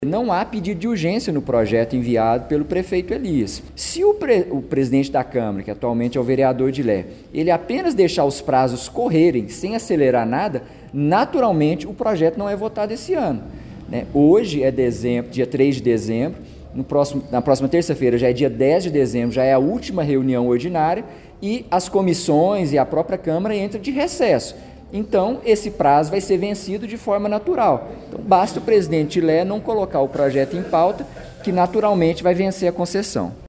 O Portal GRNEWS acompanhou a reunião ordinária da Câmara Municipal de Pará de Minas nesta terça-feira, 03 de dezembro.
Gustavo Henrique Duarte Silva disse em plenário que o presidente da Câmara está com uma bomba nas mãos, visto que não tem pedido de urgência no projeto.